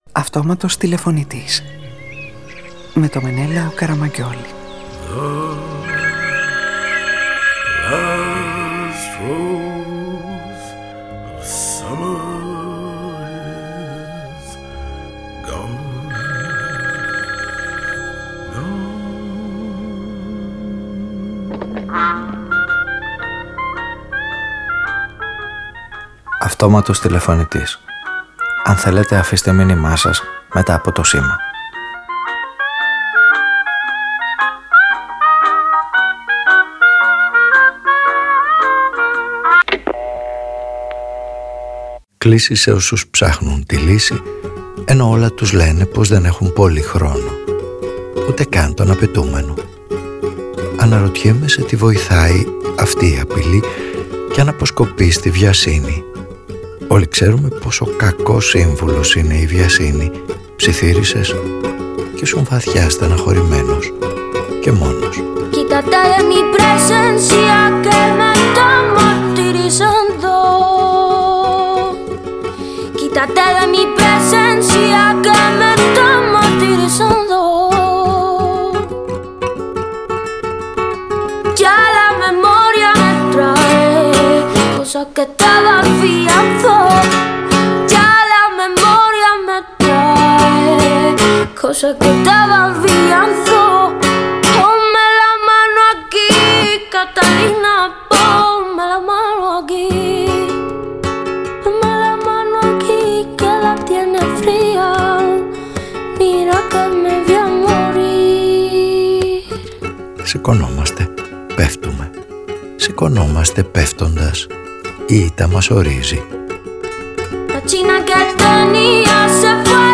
Η ΗΤΤΑ ΜΑΣ ΟΡΙΖΕΙ; Οι ήρωες της σημερινής ραδιοφωνικής ταινίας νιώθουν το χρόνο ως απειλή και για αυτό στήνουν ένα μωσαϊκό προσωπικών εξομολογήσεων με σκέψεις και φράσεις που απενοχοποιούν τις στιγμές που νιώθει κανείς λίγος, ηττημένος κι ανήμπορος.